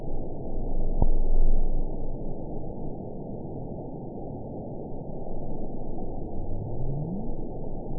event 920803 date 04/09/24 time 14:03:18 GMT (1 year ago) score 9.55 location TSS-AB05 detected by nrw target species NRW annotations +NRW Spectrogram: Frequency (kHz) vs. Time (s) audio not available .wav